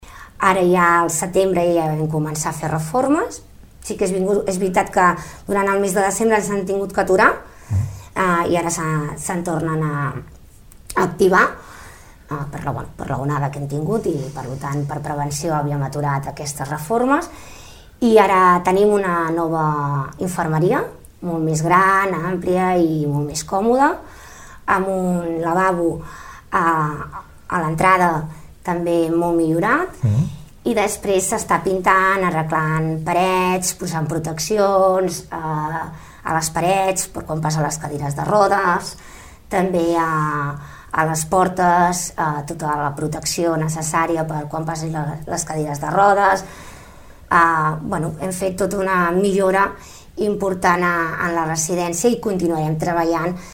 Unes obres que es van haver de suspendre però que, ara, amb la substancial millora de la situació sanitària a les residències, ja s’han pogut dur a terme. La regidora de residències, Toñi Garcia, explica quines són les millores que s’hi ha fet.